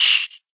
sounds / mob / silverfish / hit1.ogg